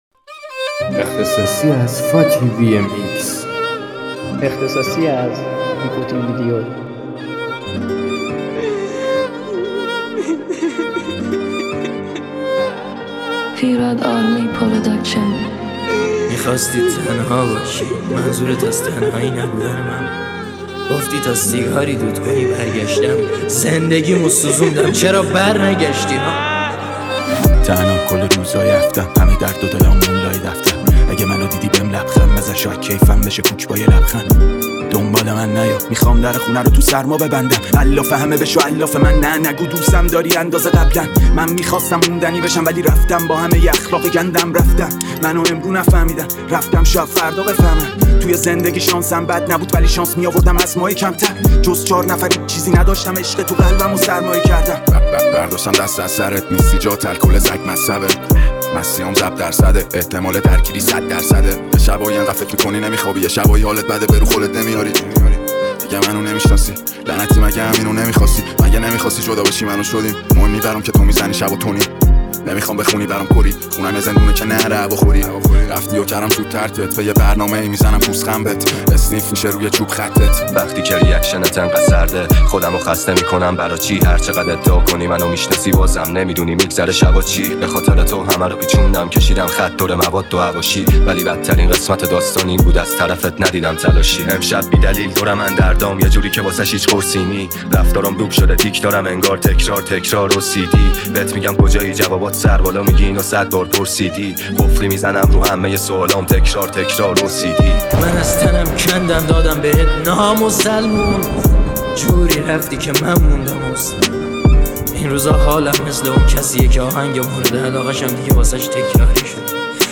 ریمیکس رپی
Remix Rapi